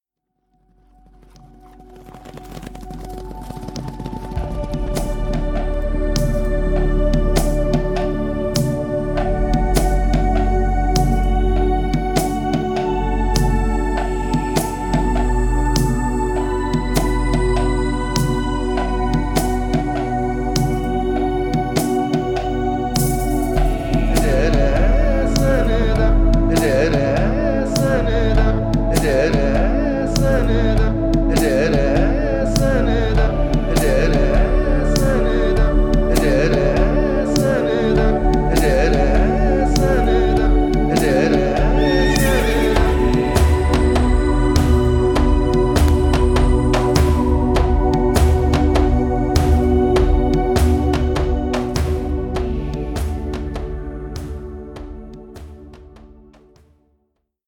Hoeren des Demos2